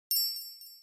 sparkle-sound.mp3